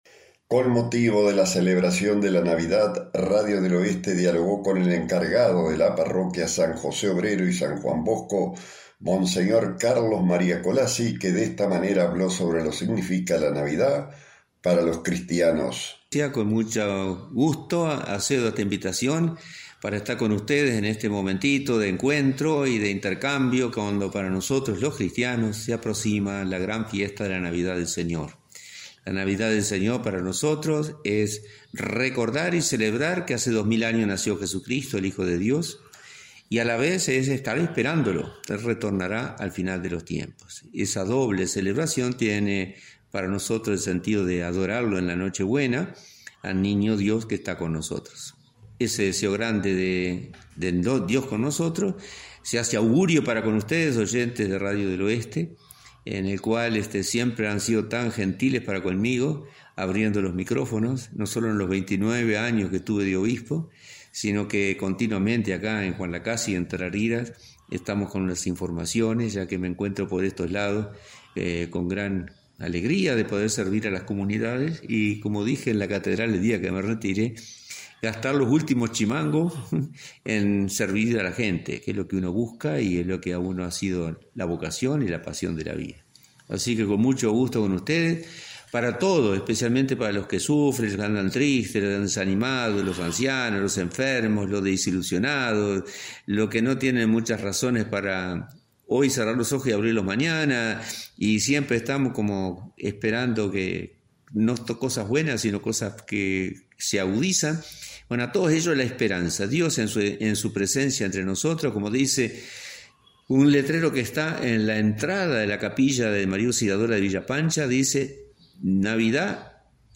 Monseñor Carlos María Collazzi dialogo con Radio del Oeste sobre lo que significa para los cristianos la navidad.